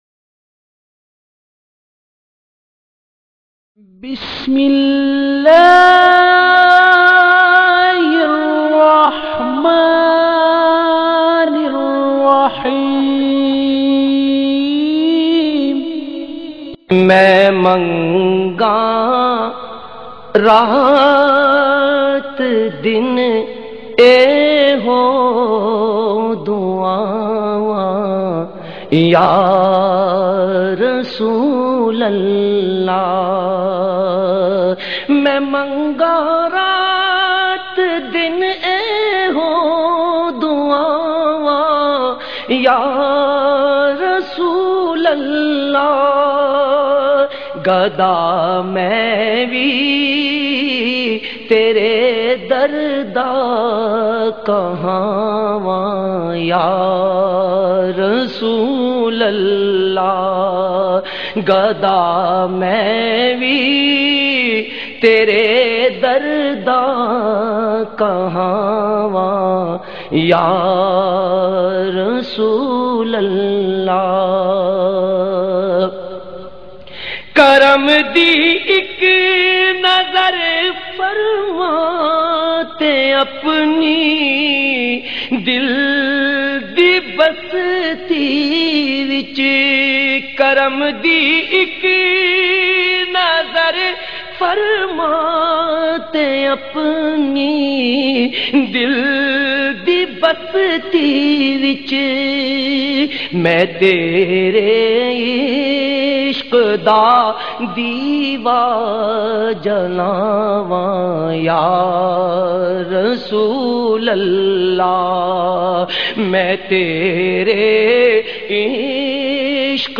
Azmat E Mustafa bayan